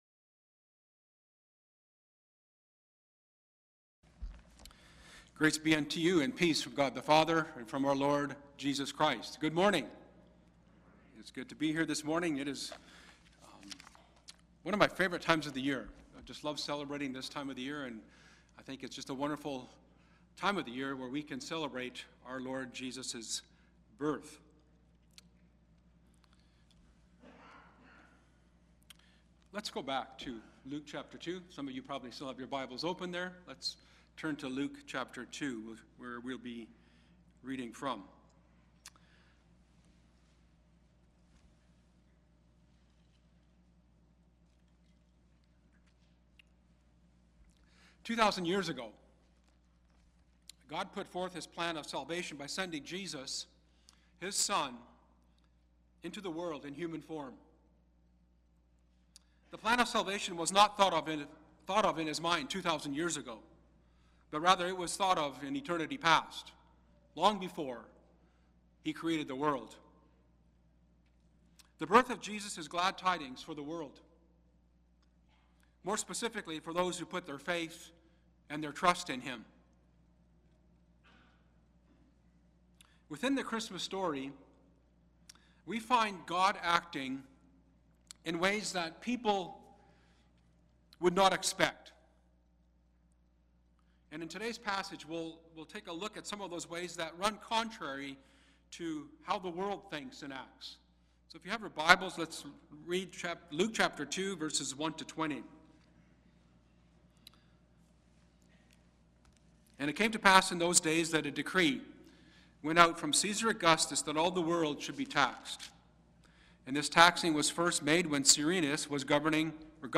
New Years Day Service